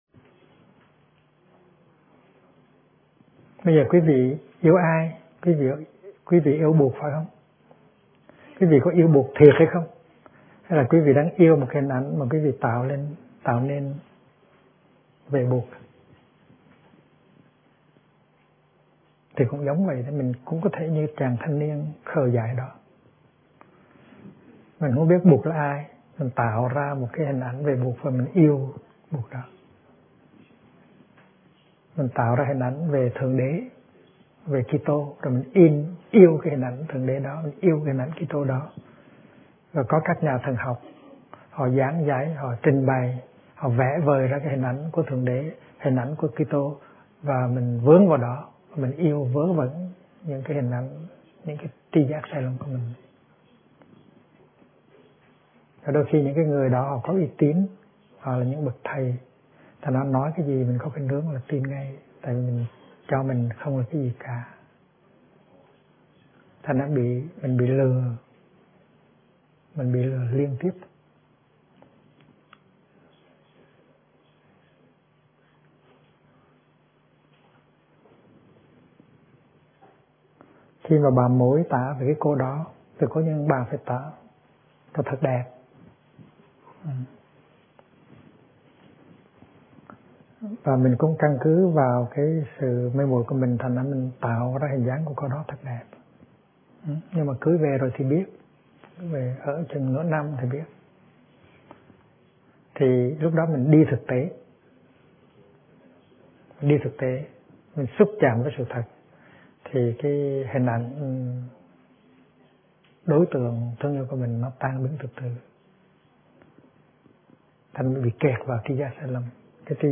Kinh Giảng Tri Kiến Chân Thật - Thích Nhất Hạnh